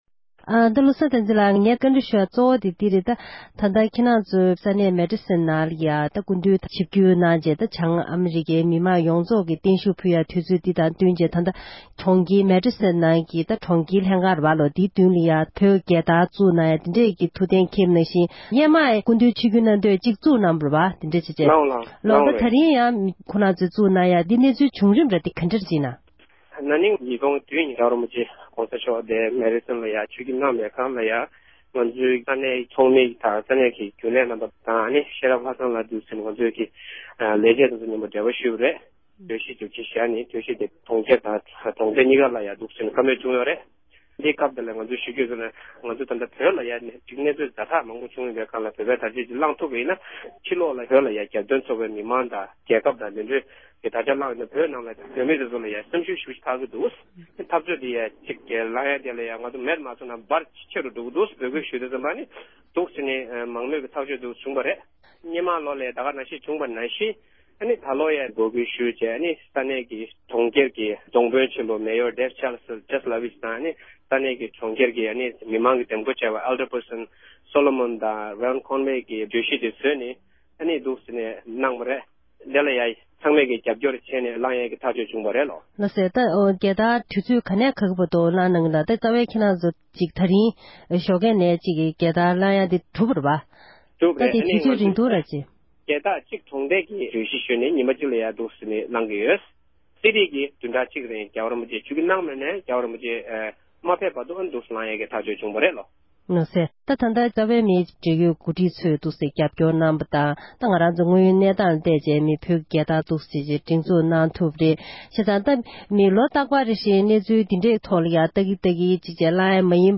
སྒྲ་ལྡན་གསར་འགྱུར། སྒྲ་ཕབ་ལེན།
བཀའ་འདྲི་ཞུས་པ་ཞིག